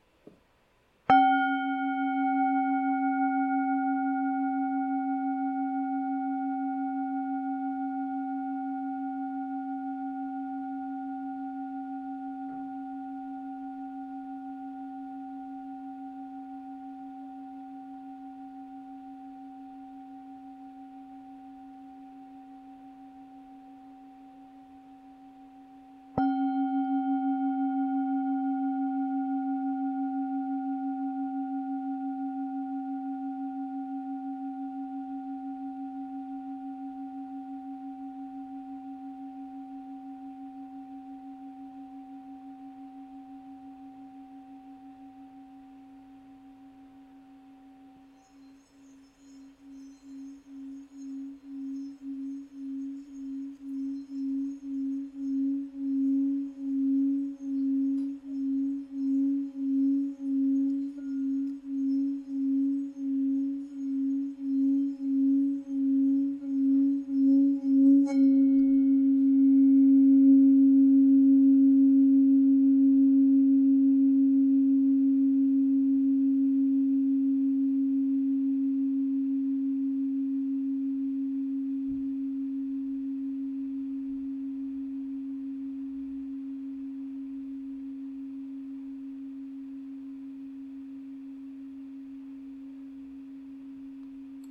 Campana tibetana per meditazione
Nota Armonica SOL(G) 5 775 HZ
Nota di fondo  DO(C) d4 275 HZ
Campana Tibetana Nota DO(C) d4 275 HZ